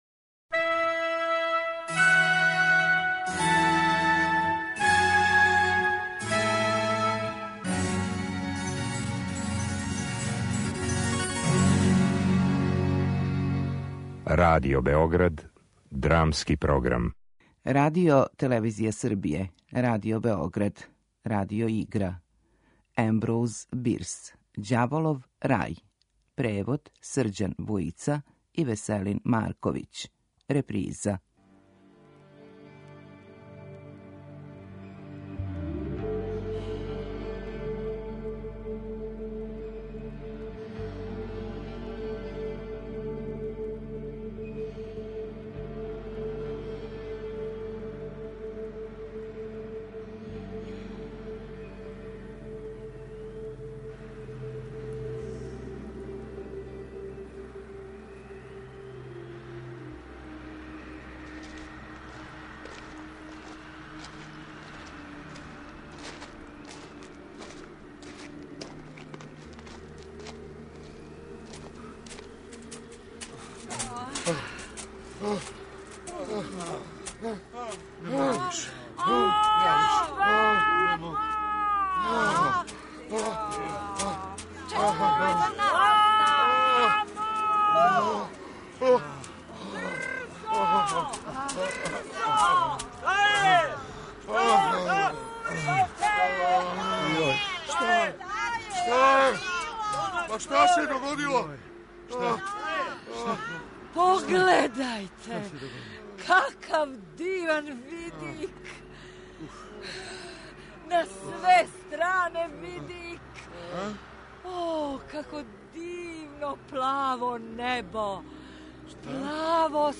Радио-игра